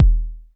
DWS KICK1 -L.wav